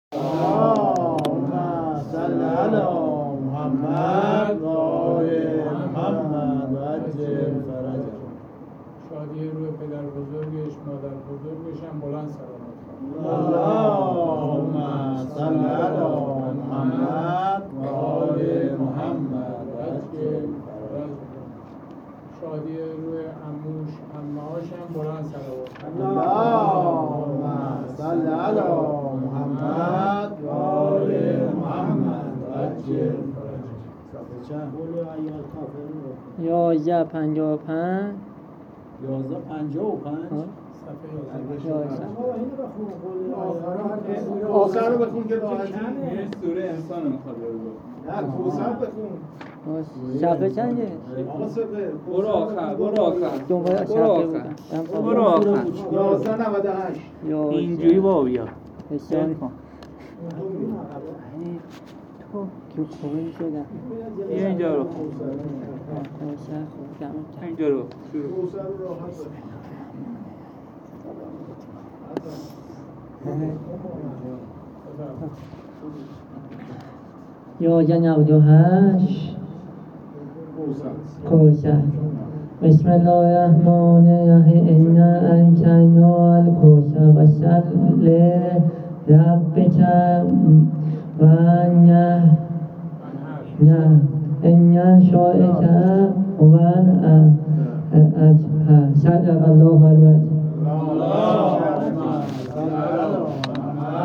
هیئت ابافضل العباس امجدیه تهران